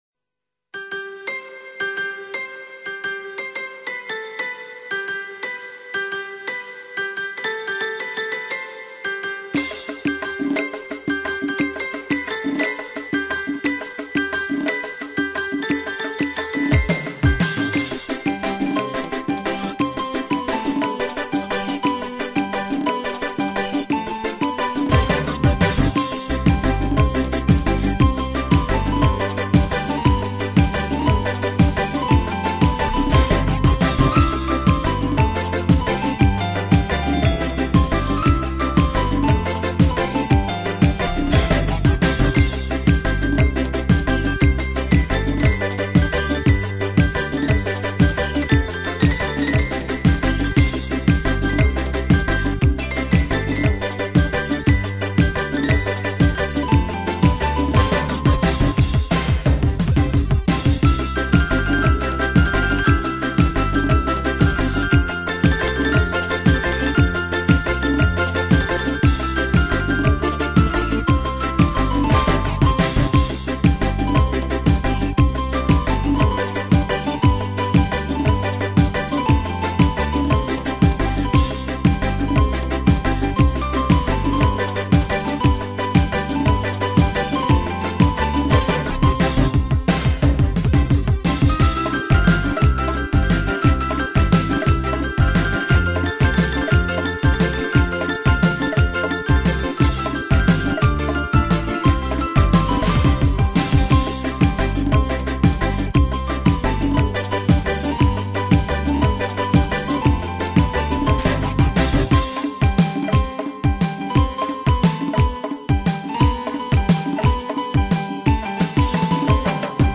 Piano-Music-Tavalod.mp3